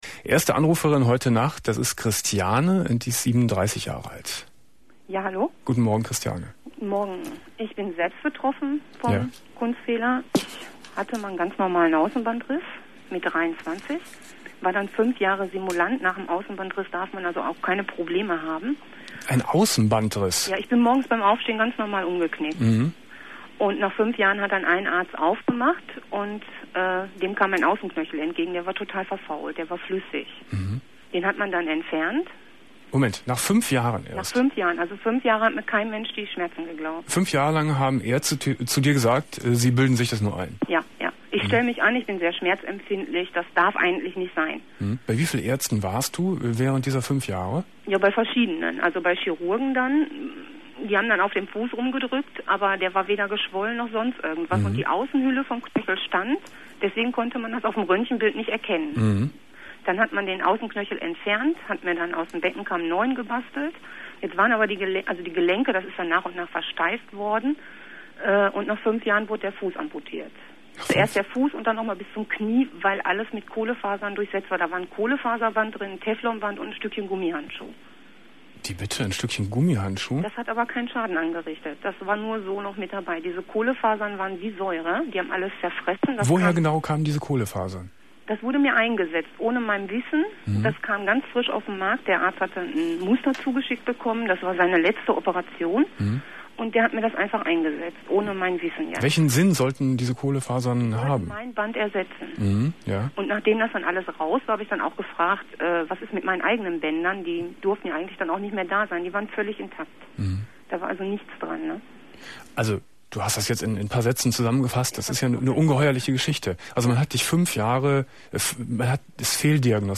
06.05.1998 Domian Thema: Ärztepfusch ~ Domian Talkradio - Das Archiv Podcast